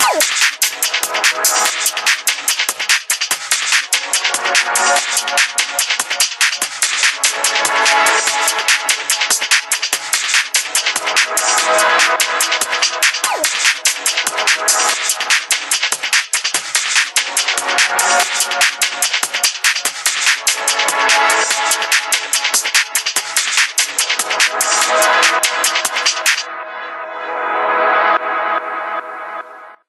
دانلود آهنگ هشدار موبایل 37 از افکت صوتی اشیاء
دانلود صدای هشدار موبایل 37 از ساعد نیوز با لینک مستقیم و کیفیت بالا
جلوه های صوتی